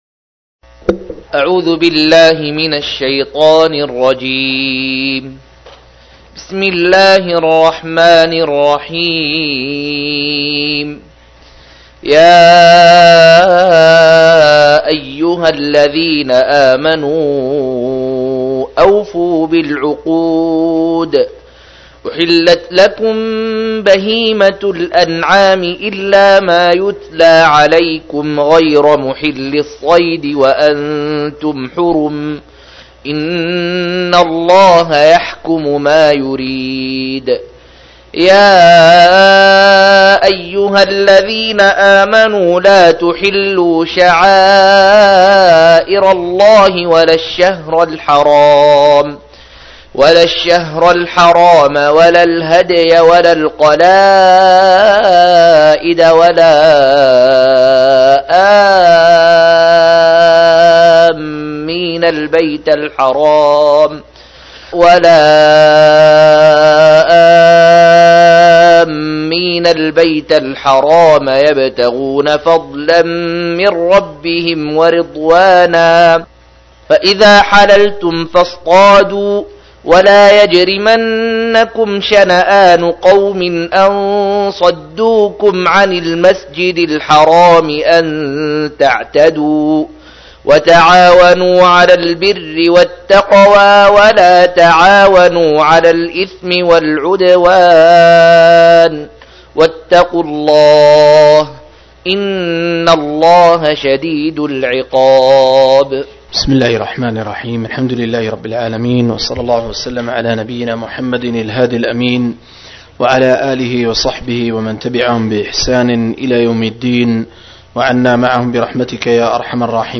107- عمدة التفسير عن الحافظ ابن كثير رحمه الله للعلامة أحمد شاكر رحمه الله – قراءة وتعليق –